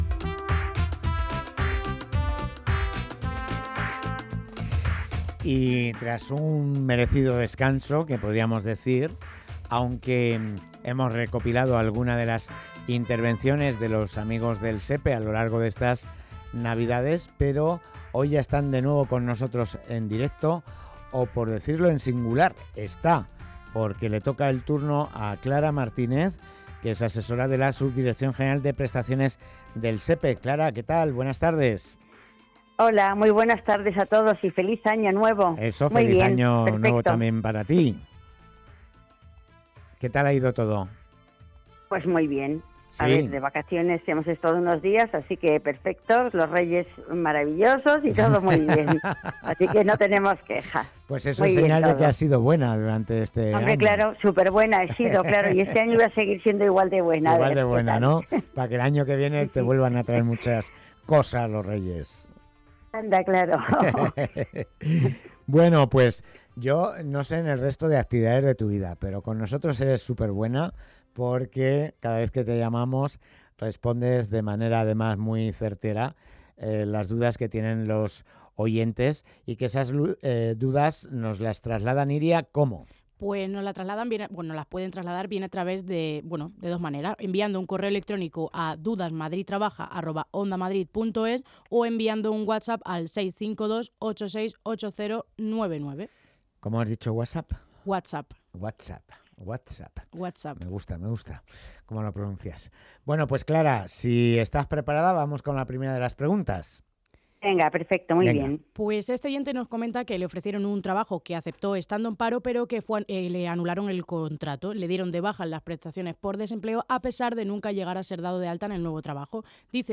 Tras el descanso navideño hemos retomado nuestra sección de consultas sobre prestaciones y subsidios del SEPE.